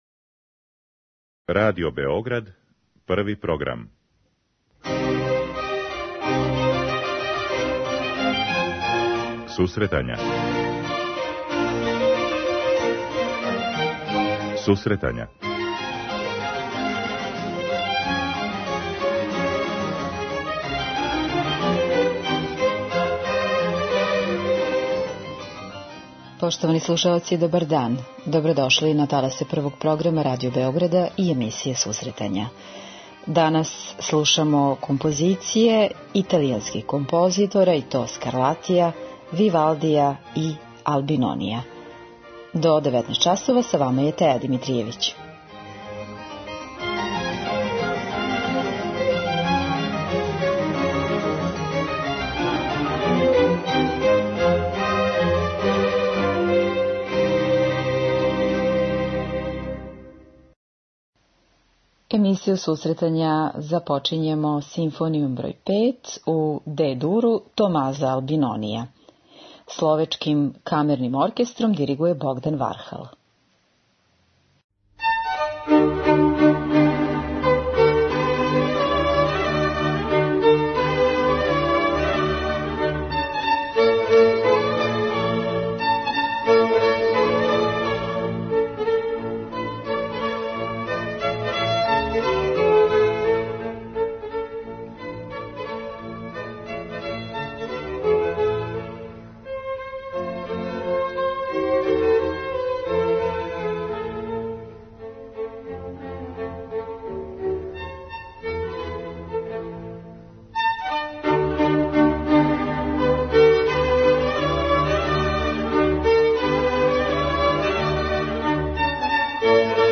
Данашње издање емисије посветили смо италијанском бароку. Слушаћемо сонате, концерте и кончерта гроса Скарлатија, Вивалдија и Албинонија.